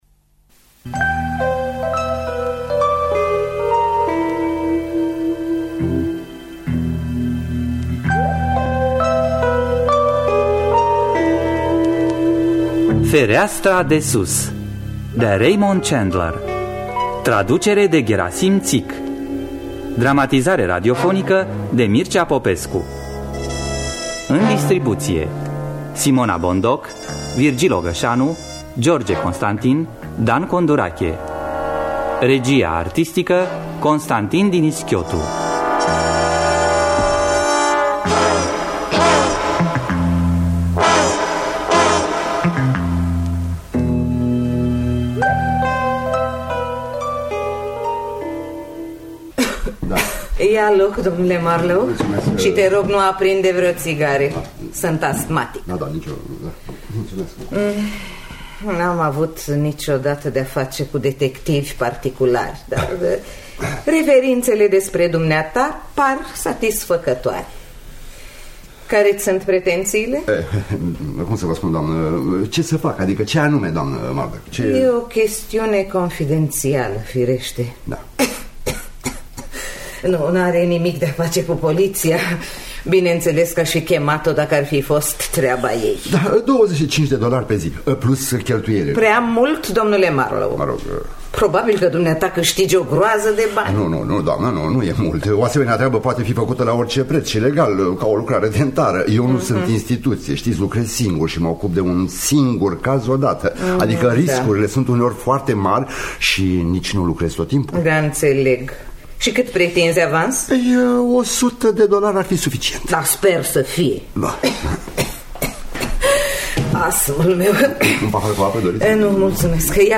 Dramatizare